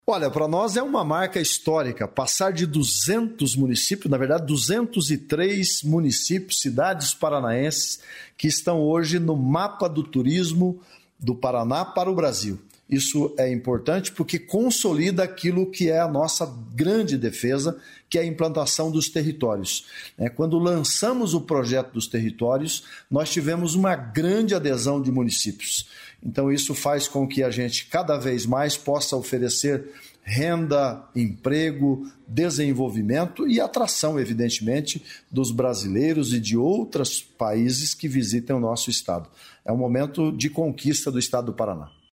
Sonora do secretário estadual do Turismo, Leonaldo Paranhos, sobre o mapa do tursimo